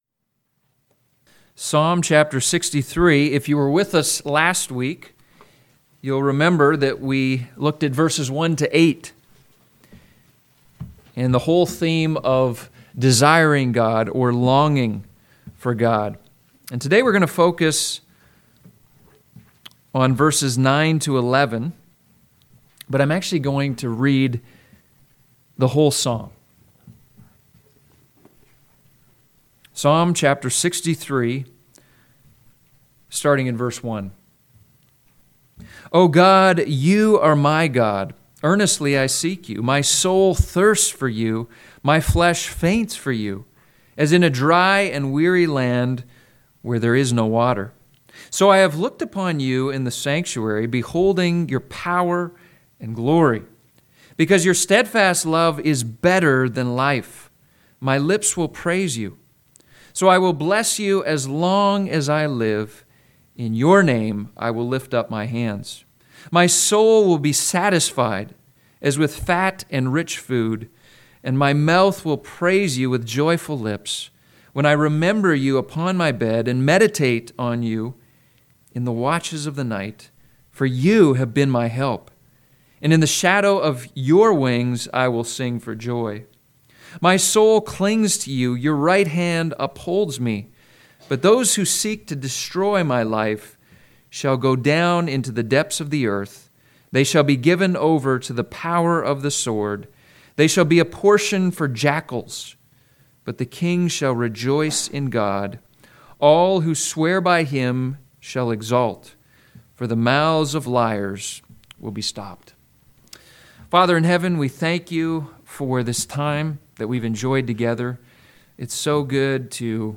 Sunday Morning Sermon